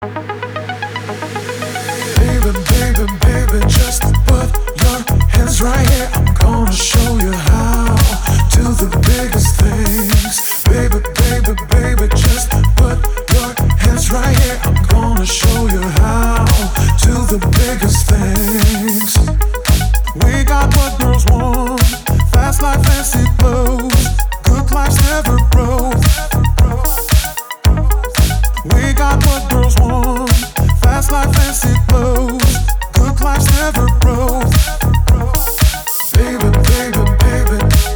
красивый мужской голос
чувственные
nu disco
house
Жанр: Танцевальная/электронная музыка